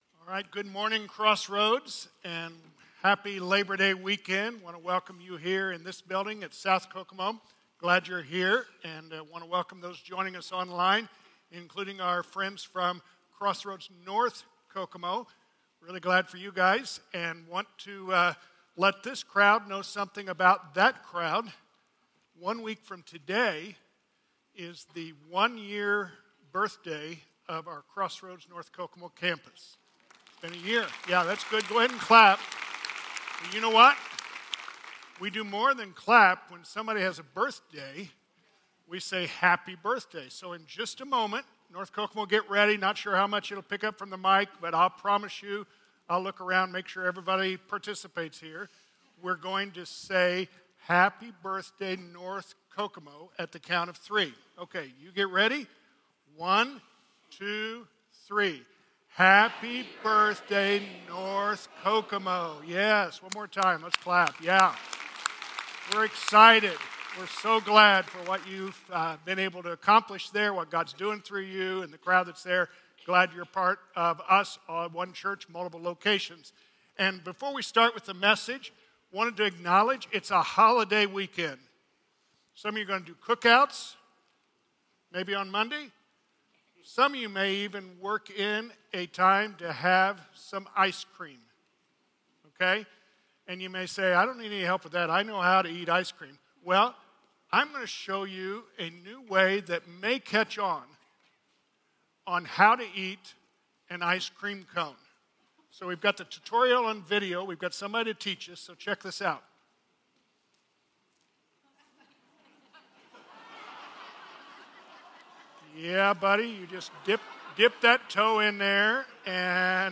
Crossroads Community Church - Audio Sermons